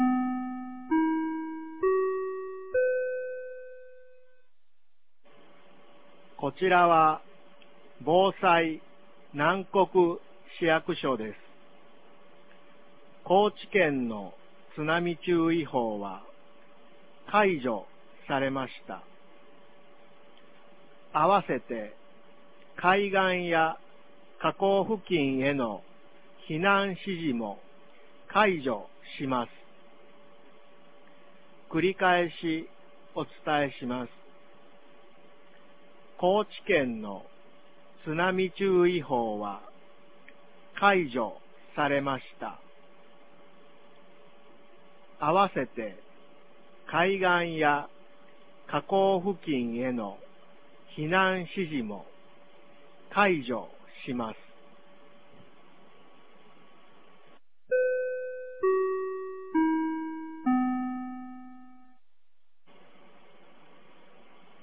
2022年01月16日 14時16分に、南国市より放送がありました。